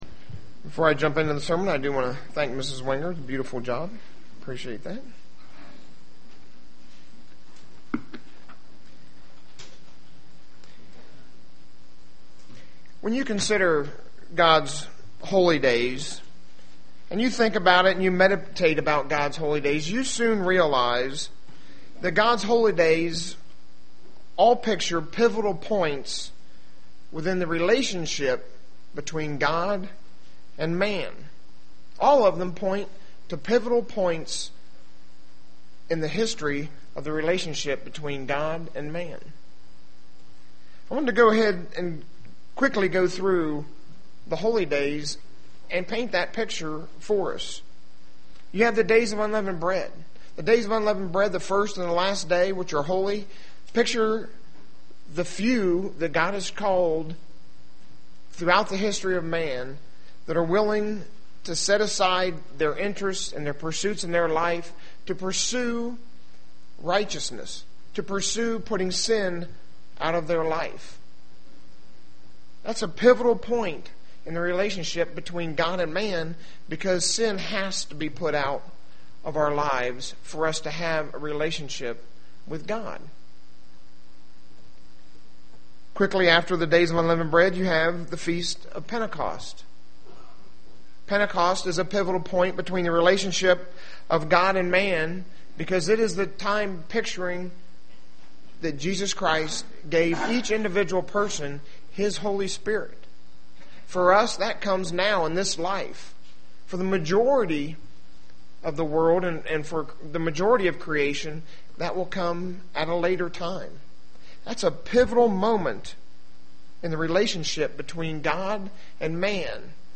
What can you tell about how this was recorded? This message was given on the Day of Atonement.